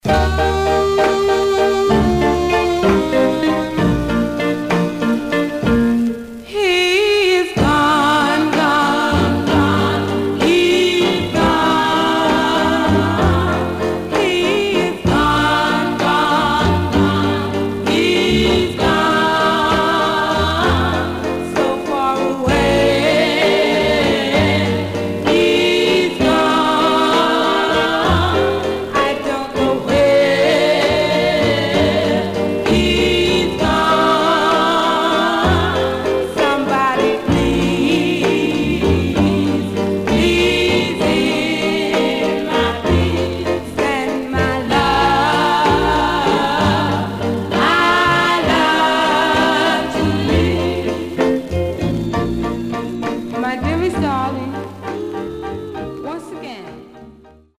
Stereo/mono Mono
Black Female Group